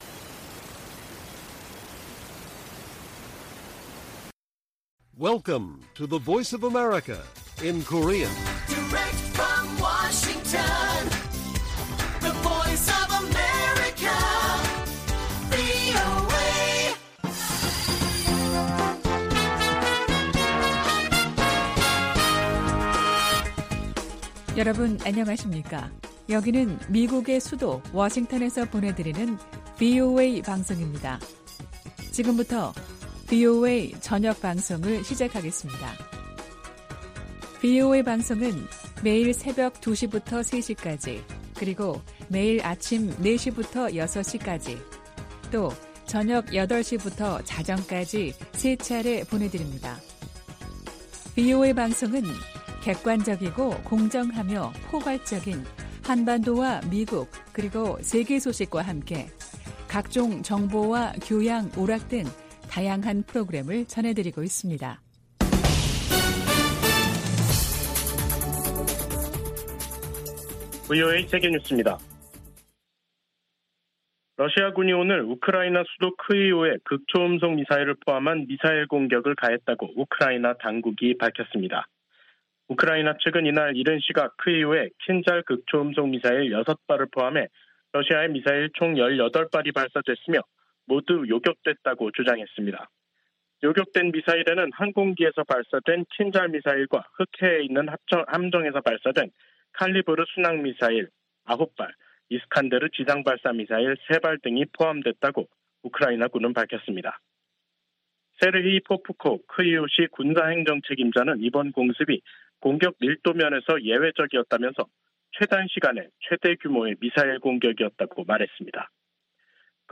VOA 한국어 간판 뉴스 프로그램 '뉴스 투데이', 2023년 5월 16일 1부 방송입니다.